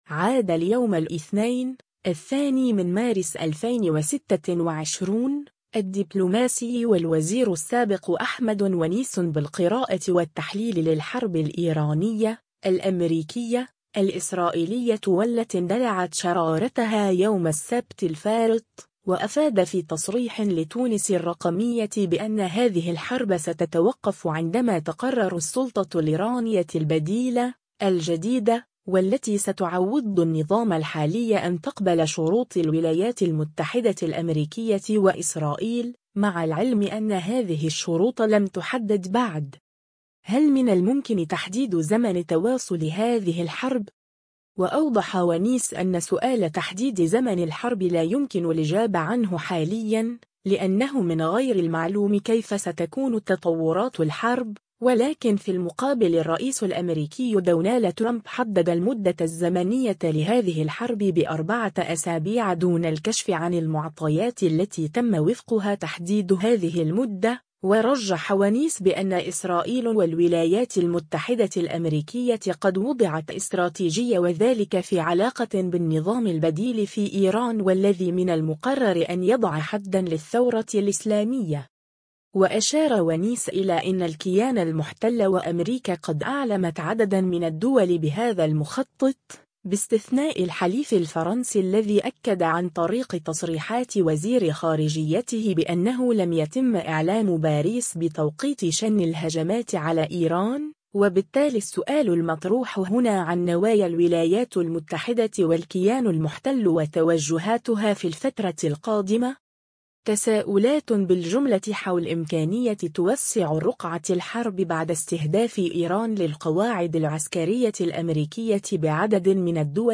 عاد اليوم الإثنين، 02 مارس 2026، الدّبلوماسي و الوزير السّابق أحمد ونيس بالقراءة و التحليل للحرب الإيرانية -الأمريكية، الإسرائيلية و التي اندلعت شرارتها يوم السبت الفارط، و أفاد في تصريح لتونس الرّقمية بأنّ هذه الحرب ستتوقف عندما تقرّر السلطة الايرانية البديلة، الجديدة، و التي ستعوضّ النّظام الحالي أن تقبل شروط الولايات المتحدة الأمريكية و إسرائيل، مع العلم أنّ هذه الشروط لم تحدد بعد…